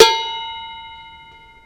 描述：These are the sounds recorded by cooking utensils such as pots, pans .... They are made of metal and wood stick.Recorded with Stagg PGT40 microphone, Digidesignmbox (original) Wave Recorder in little isolated room.Files are mono , 16,44khz.Processed with wave editor.Pack, set "syd".
标签： percussion sfx acoustic